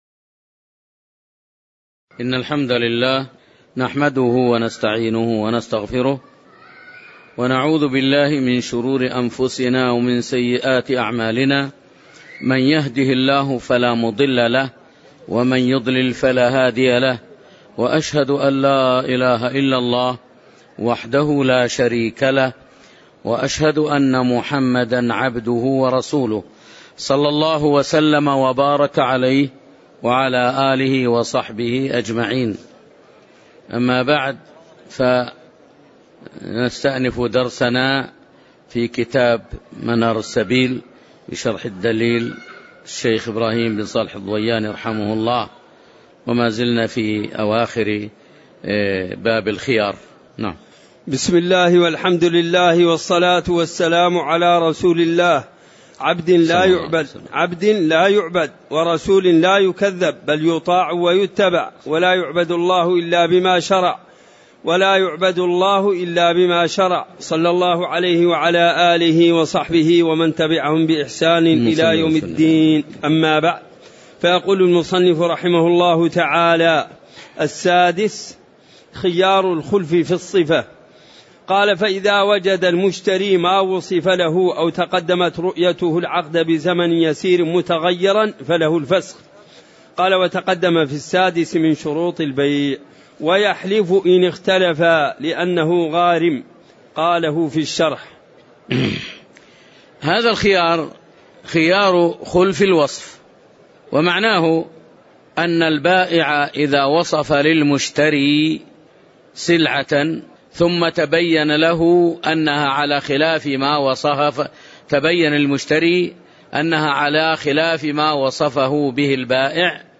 تاريخ النشر ١ صفر ١٤٤٠ هـ المكان: المسجد النبوي الشيخ